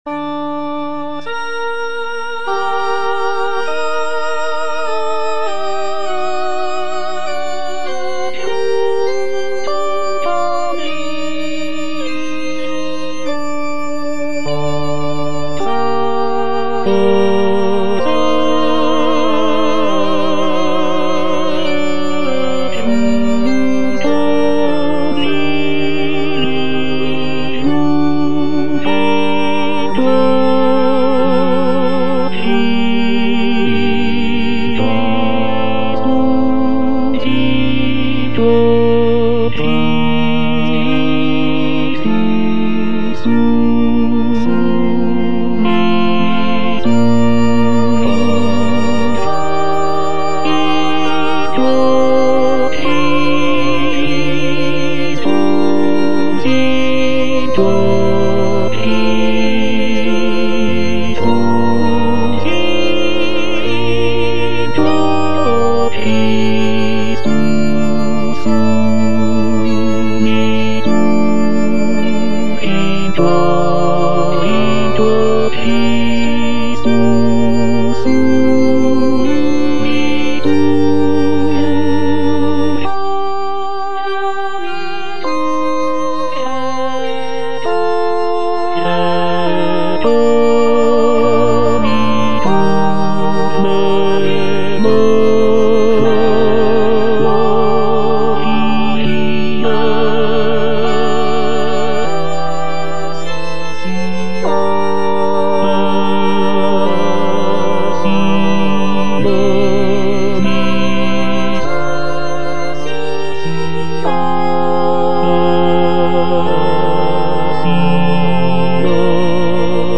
G.B. PERGOLESI - O SACRUM CONVIVIUM Tenor (Emphasised voice and other voices) Ads stop: auto-stop
"O sacrum convivium" is a sacred choral motet composed by Giovanni Battista Pergolesi in the early 18th century. The piece is written for four-part choir and is renowned for its expressive and devotional qualities.